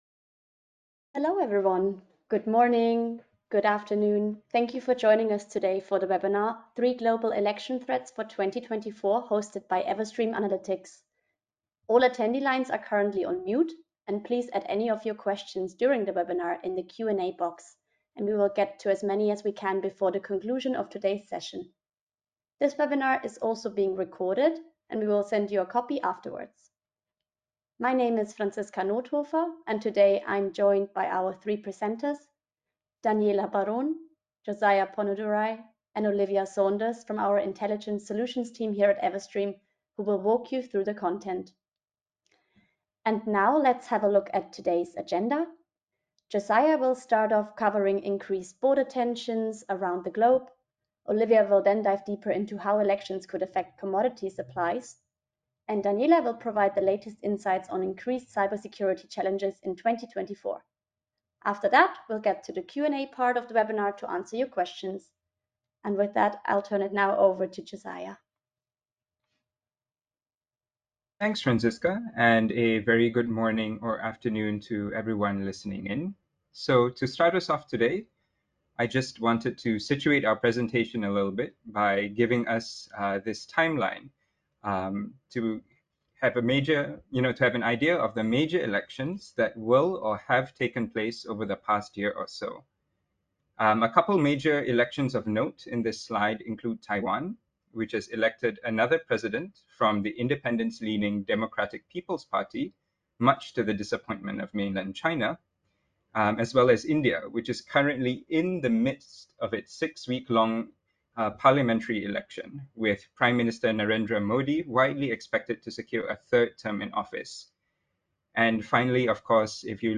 Our panelists share supply chain specific insights, offer guidance, and create outlook scenarios.
EA_ThreeGlobalThreats_SupplyChainWebinar_AudioOnly.mp3